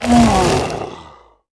fall_1.wav